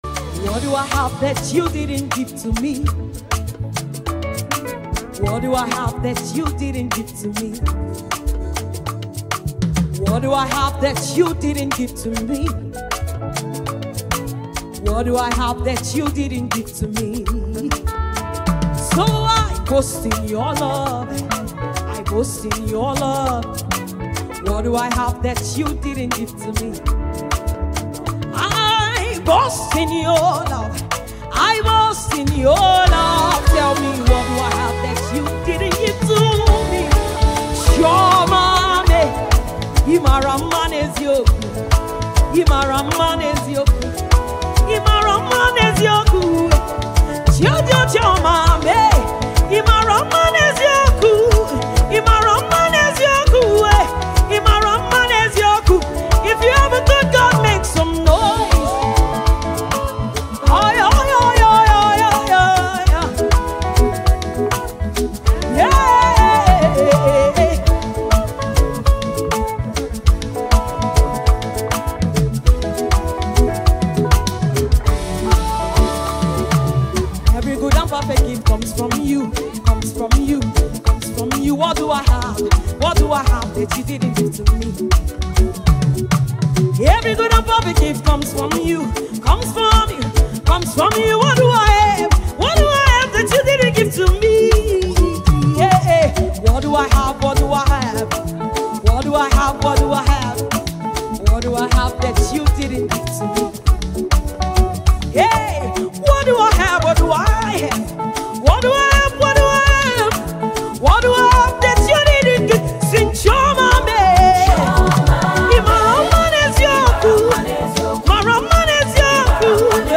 Mp3 Gospel Songs
Talented Nigerian female gospel singer and songwriter